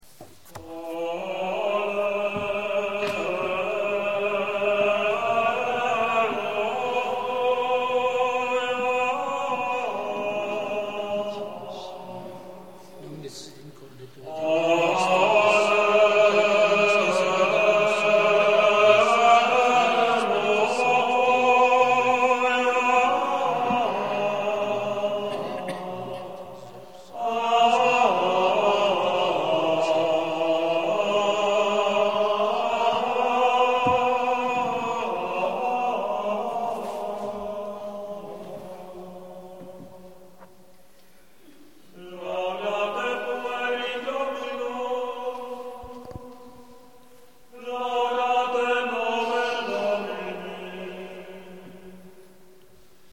Gregoriánský chorál